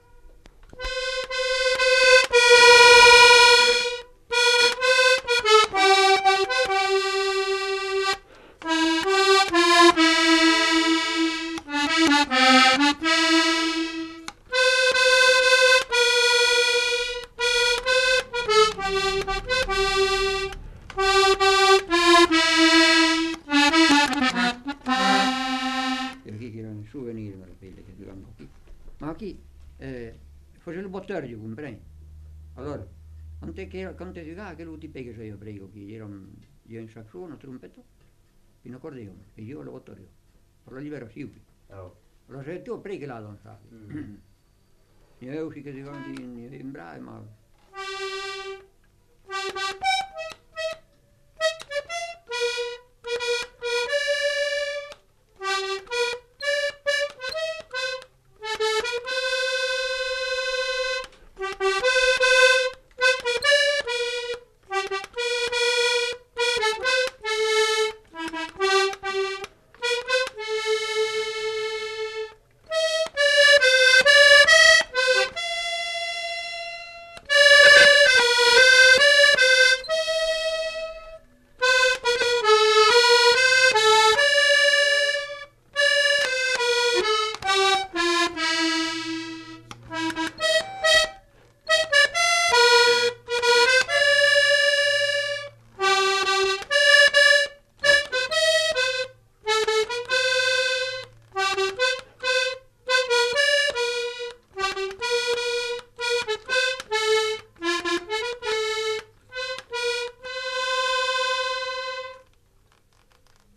Instrumental. Accordéon diatonique
Genre : morceau instrumental
Instrument de musique : accordéon diatonique